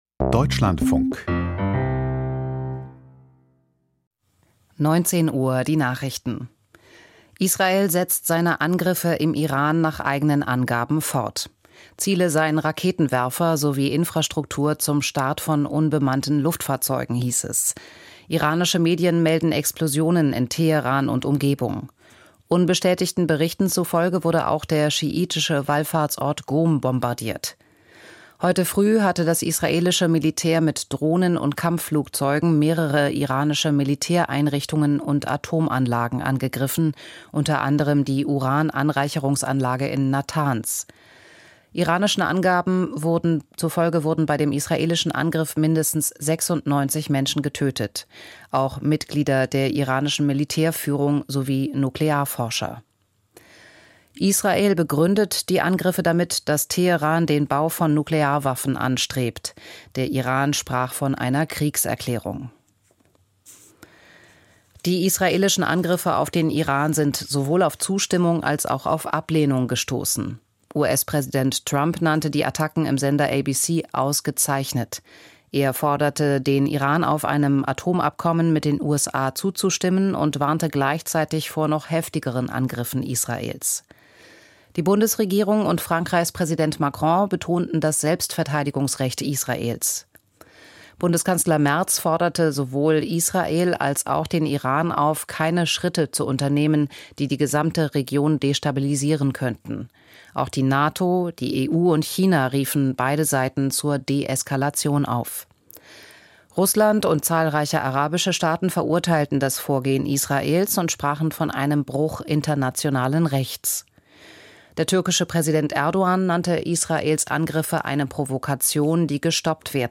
Die Nachrichten vom 13.06.2025, 19:00 Uhr